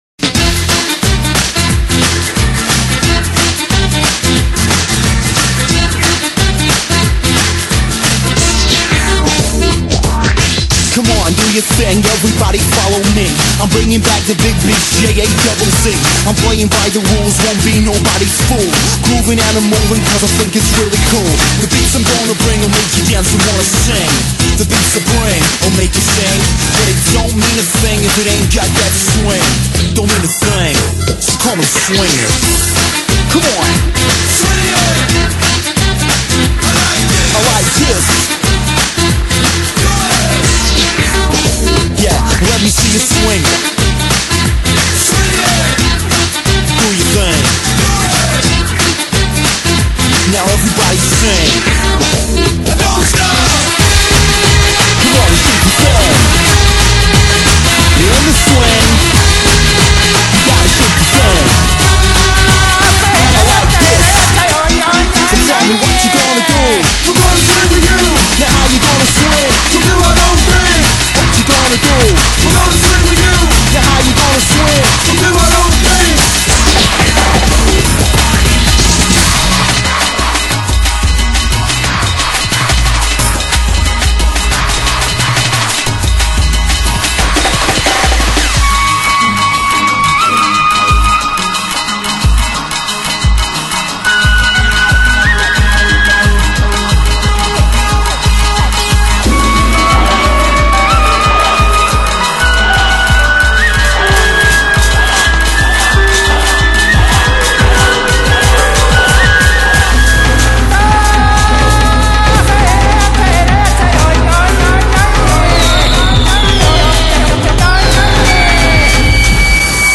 BPM49-196
Audio QualityPerfect (High Quality)